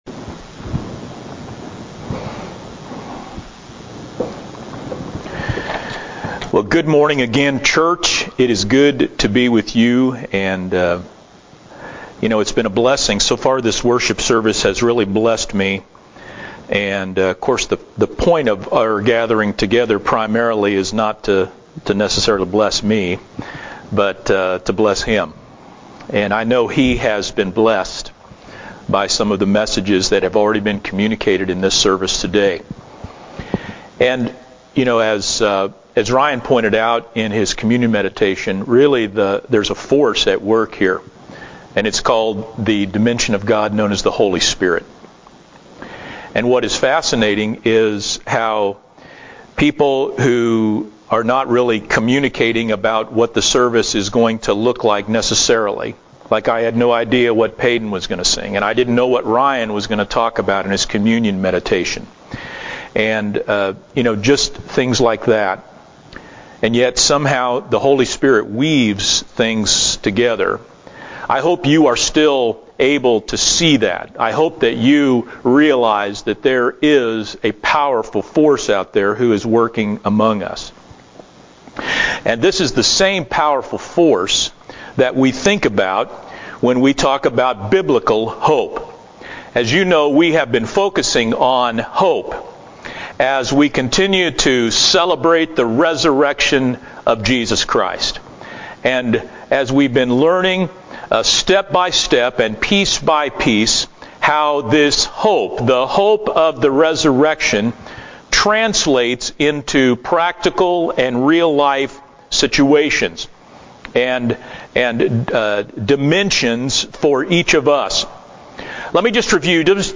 5-03-20-Sermon-Only-_Hope-in-the-Resurrection-Launc-CD.mp3